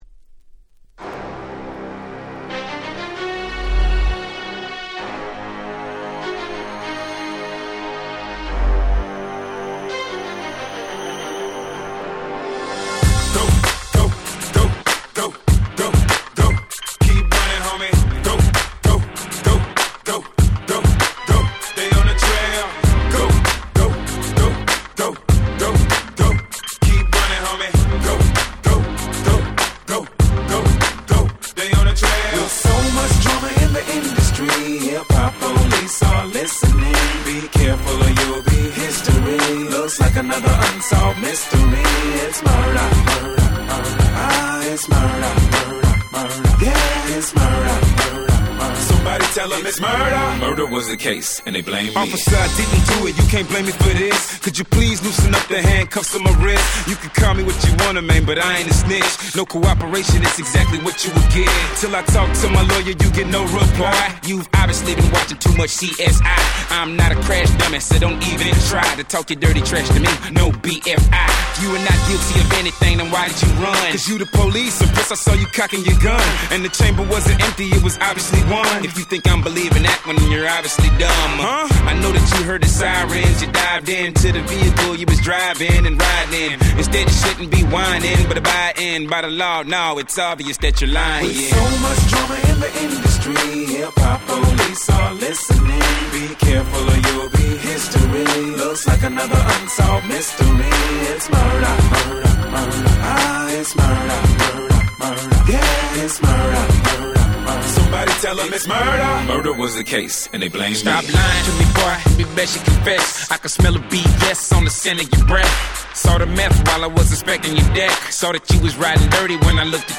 07' Smash Hit Hip Hop !!
Boom Bap ブーンバップ